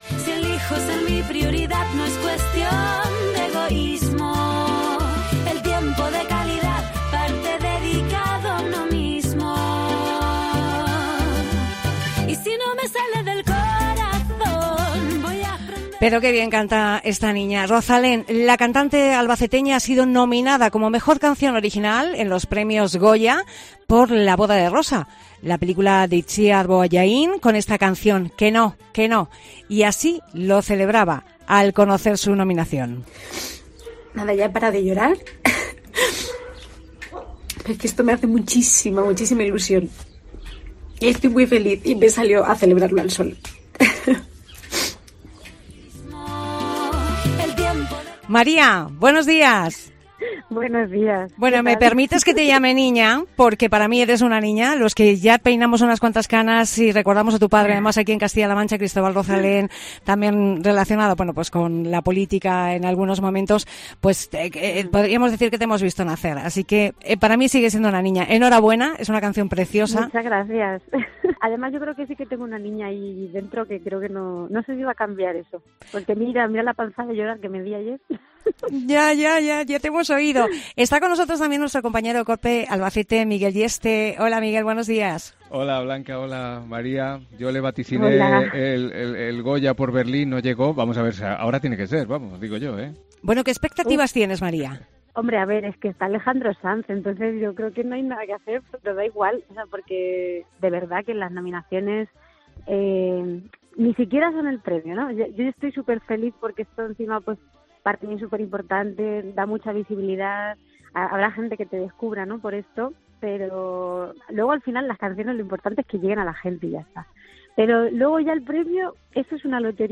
Entrevista a Rozalén